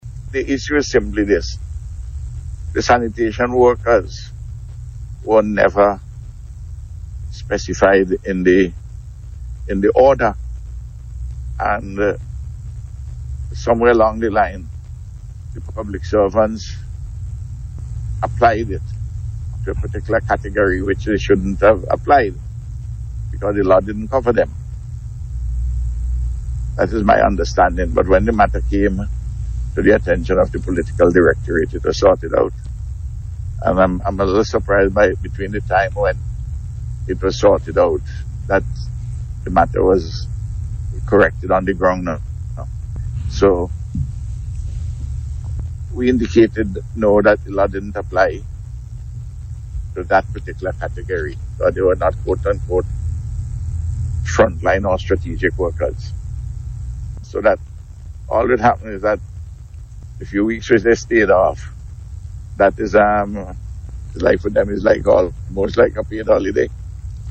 Prime Minister Dr. Ralph Gonsalves spoke on the issue on Radio this morning.